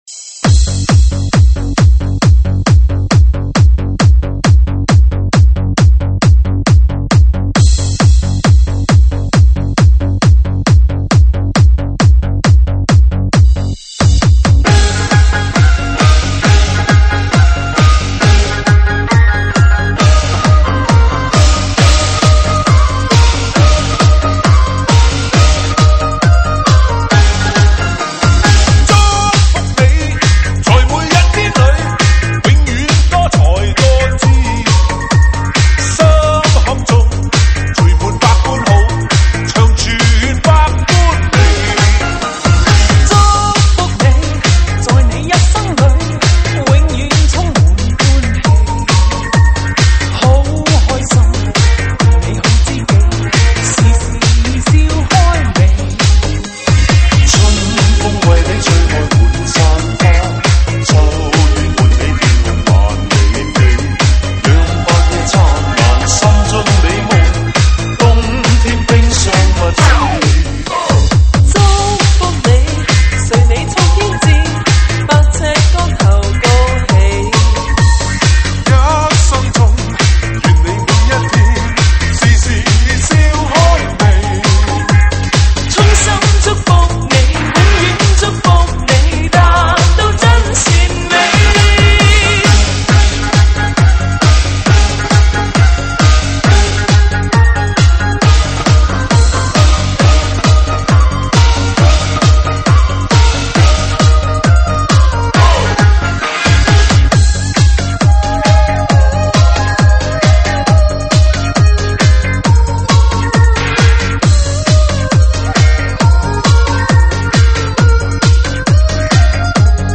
新年喜庆